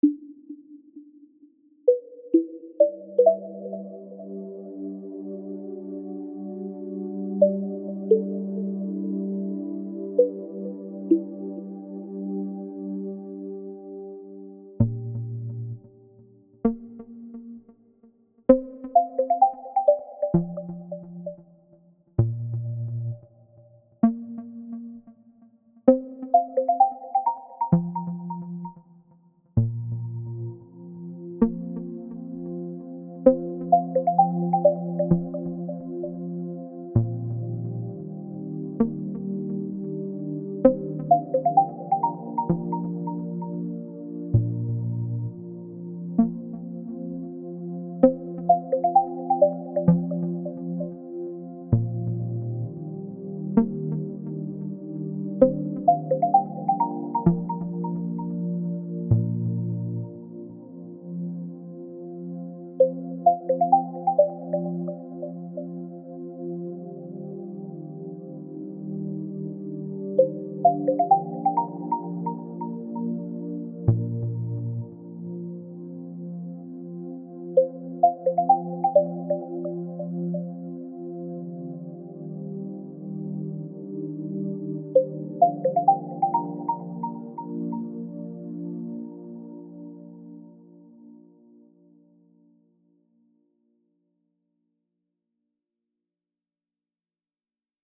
Inspired by ambient music from game consoles that we all grew up with, specifically Nintendo's Wii U and Nintendo 3DS system music.